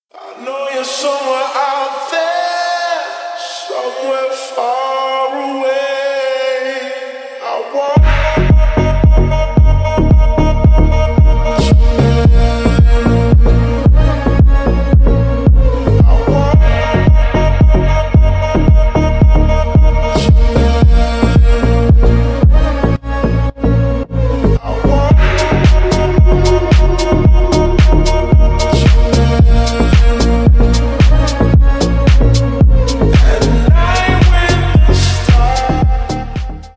Ремикс
клубные